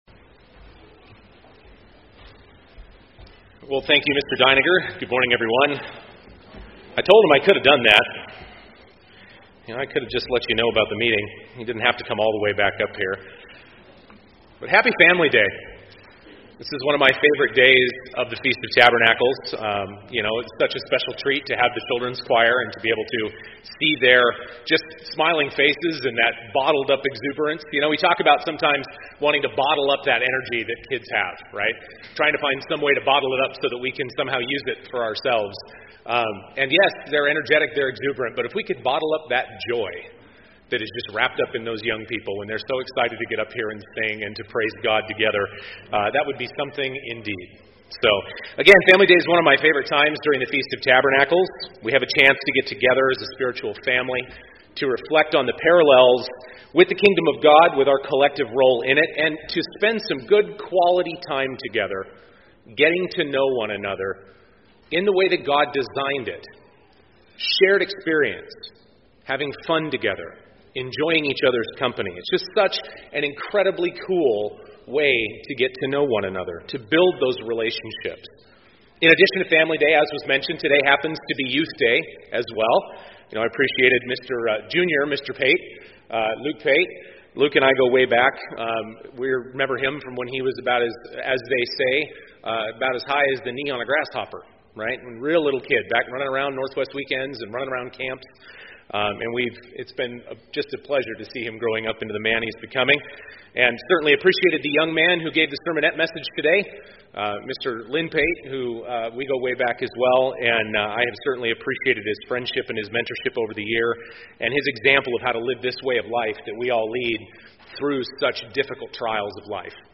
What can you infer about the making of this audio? This sermon was given at the Glacier Country, Montana 2018 Feast site.